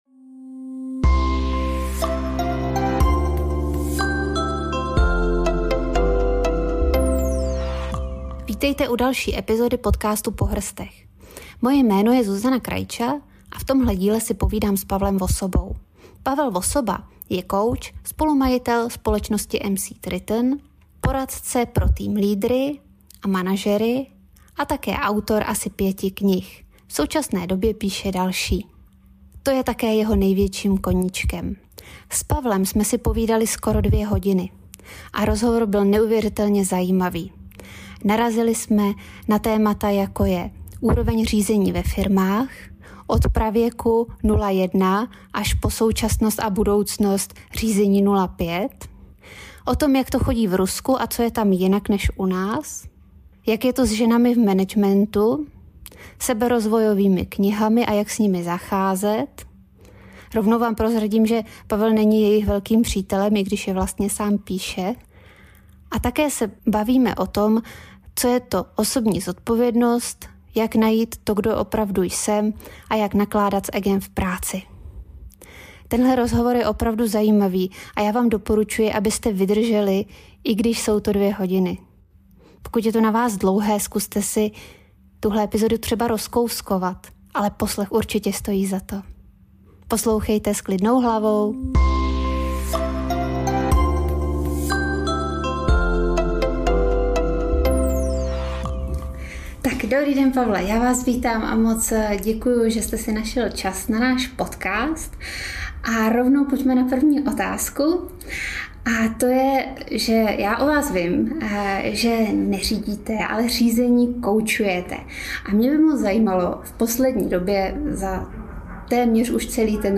V rozhovoru probíráme pestrou škálu témat – ženy v managementu, osobní zodpovědnost, jak nakládat s egem v práci nebo důležitost seberozvojových knížek.